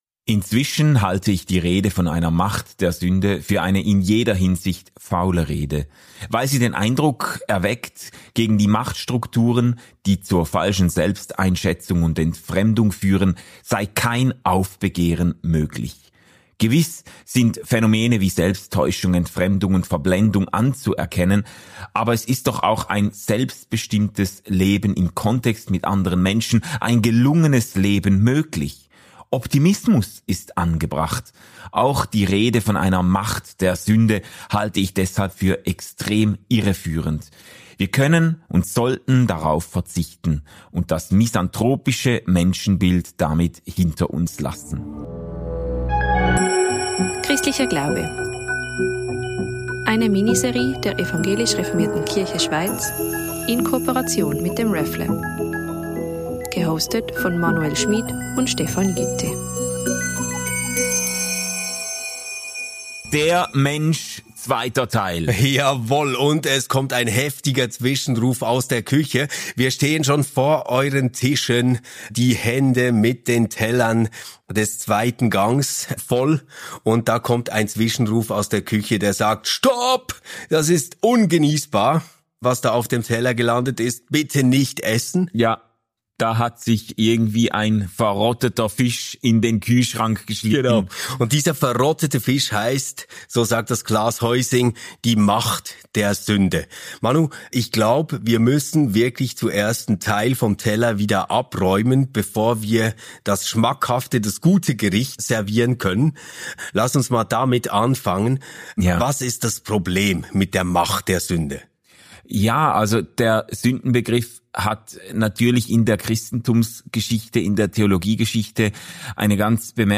unterhalten sich